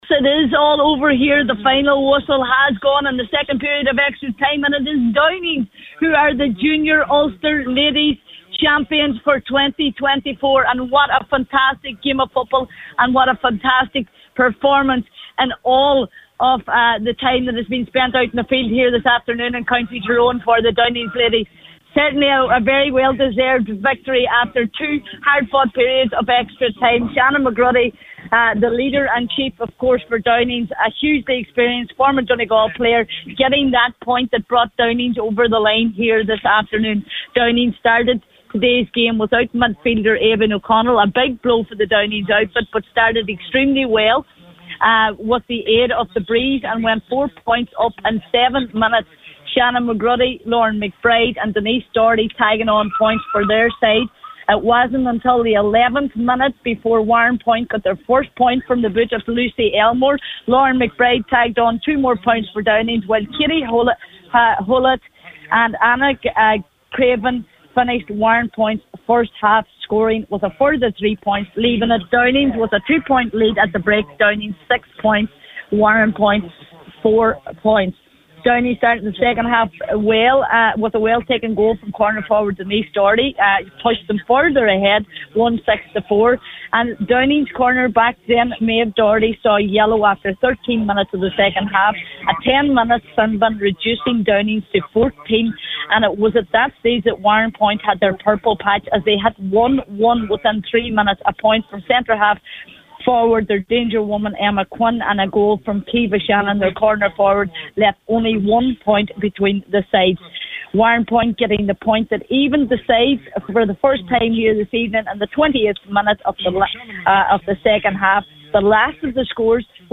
has the full time report from Kildress…